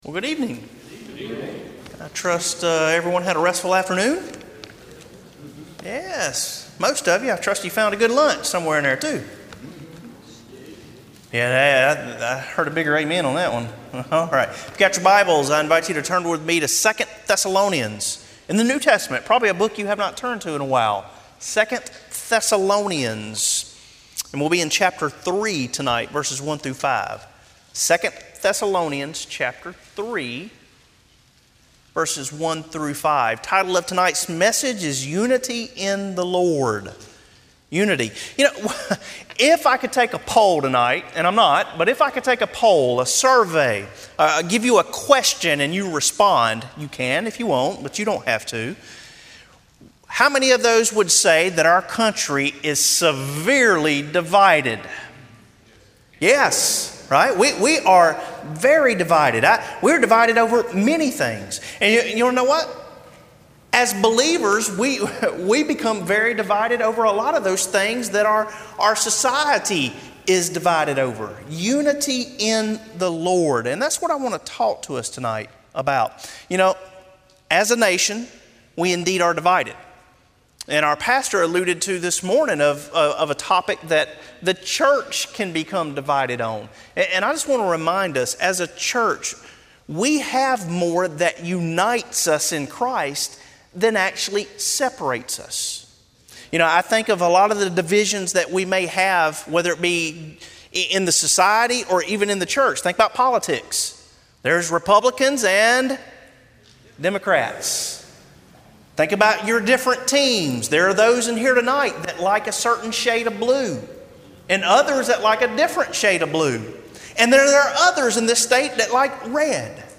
Sermon Audios/Videos - Tar Landing Baptist Church
Evening Worship2 Thessalonians 3:1-5